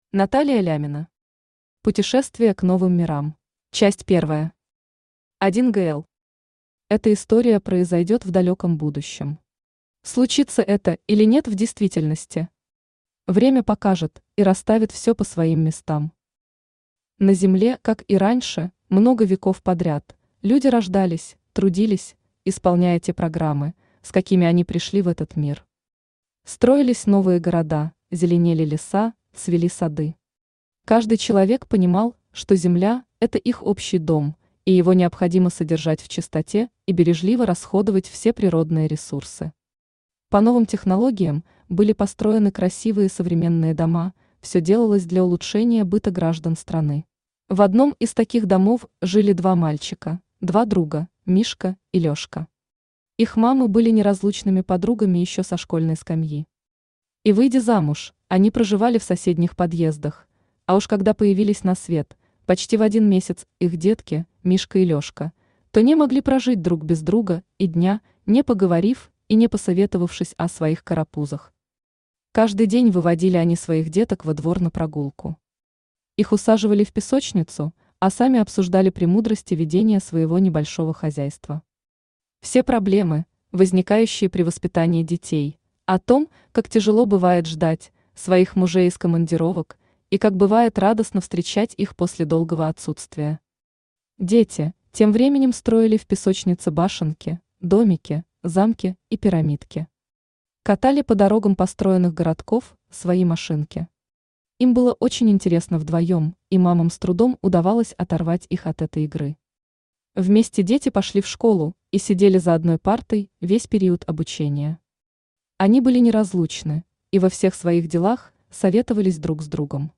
Аудиокнига Путешествие к новым мирам | Библиотека аудиокниг
Читает аудиокнигу Авточтец ЛитРес.